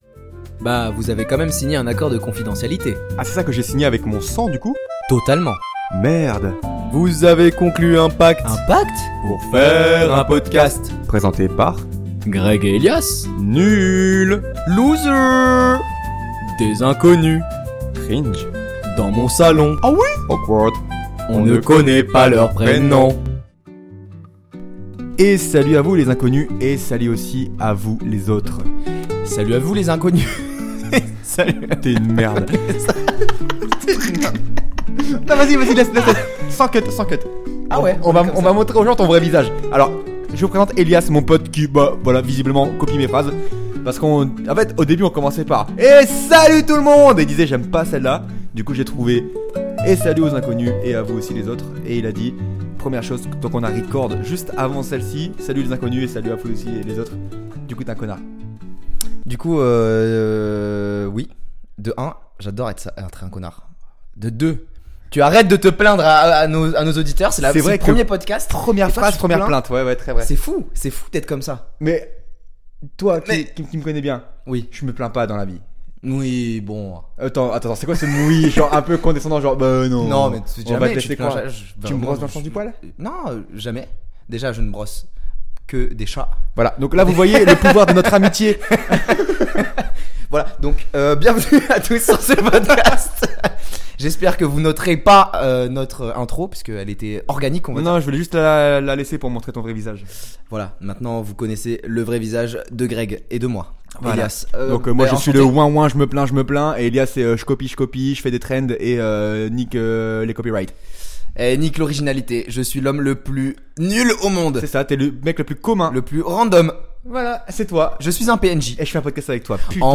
Attendez-vous à des discussions rocambolesques, des jeux improvisés et des réflexions inattendues.. À travers des échanges empreints de joie et de bonne humeur, nos invités se livrent avec spontanéité, distillant au passage de précieuses leçons de vie.